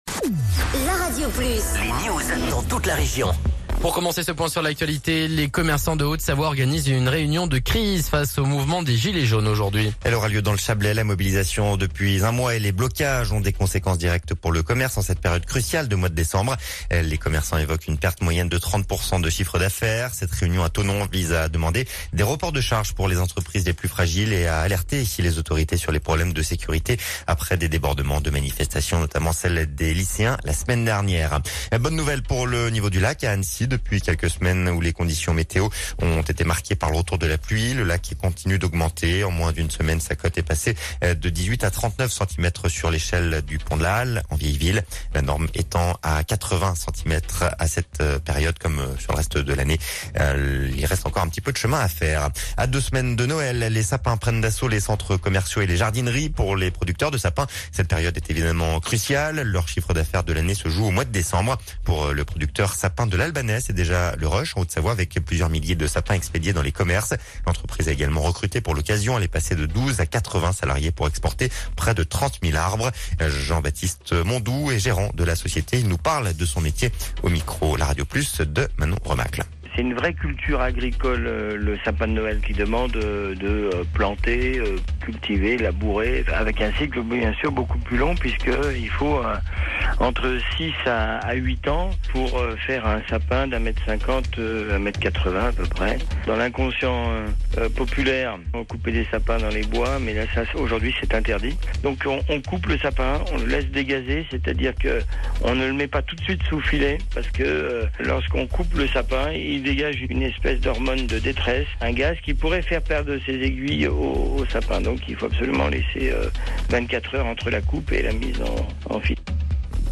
Reportage sur le contrat ambition de la région pour la vallée du Giffre